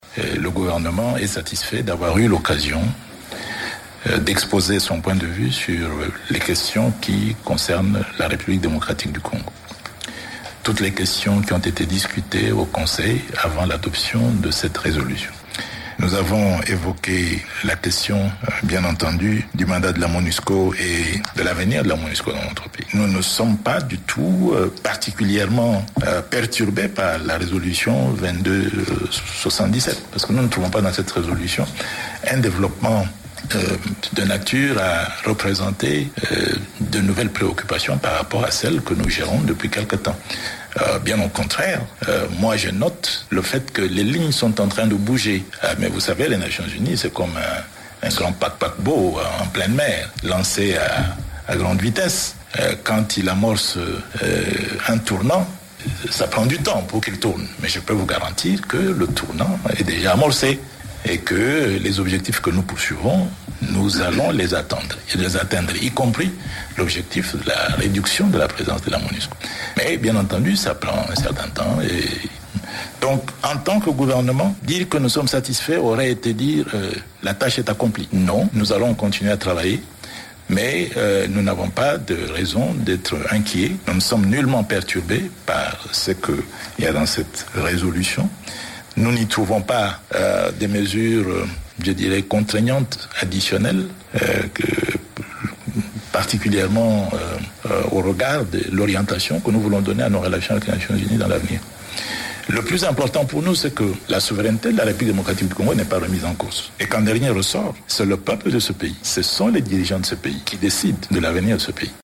Extrait du point de presse du ministre congolais des Affaires étrangères Raymond Tshibanda/Radio Top Congo FM